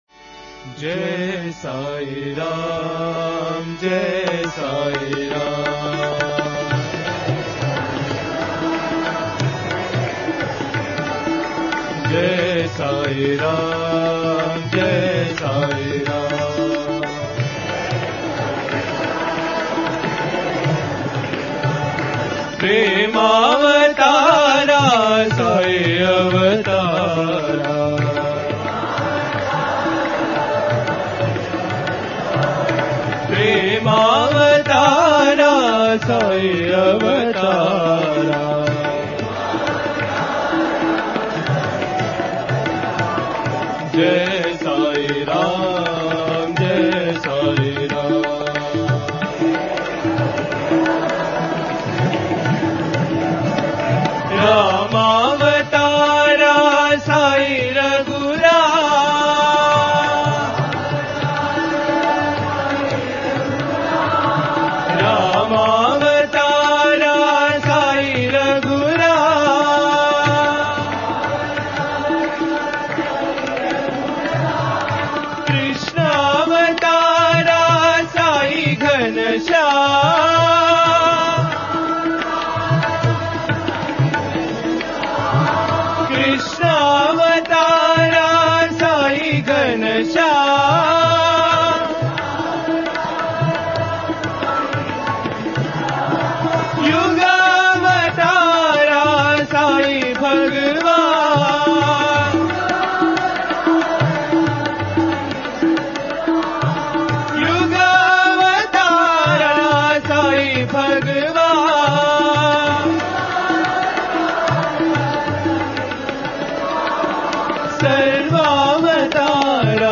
Related Bhajan